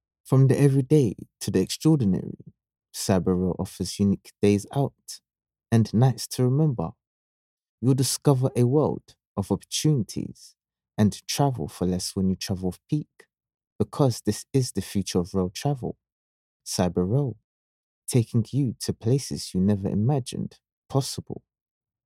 English (Caribbean)
Yng Adult (18-29) | Adult (30-50)